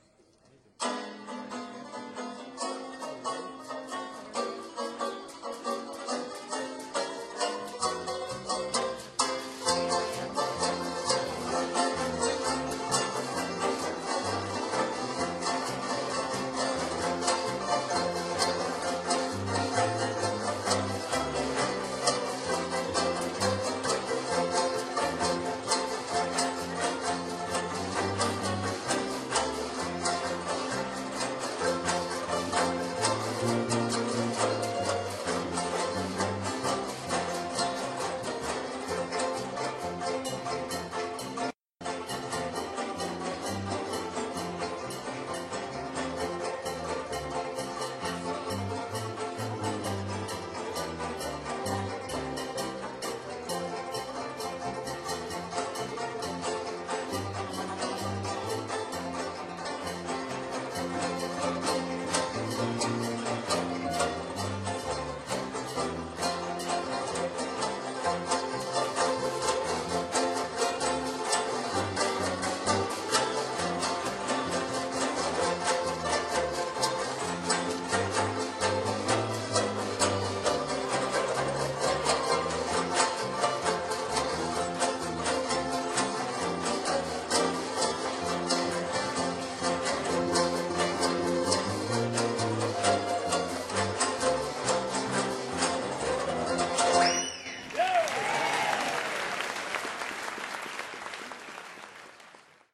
8-beat intro.
Listen to the Oakland Banjo Band perform "Down South" (mp3)